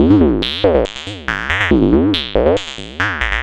tx_synth_140_mhhahh1.wav